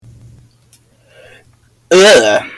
Play, download and share Loud Ugh! original sound button!!!!